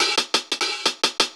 Index of /musicradar/ultimate-hihat-samples/175bpm
UHH_AcoustiHatA_175-03.wav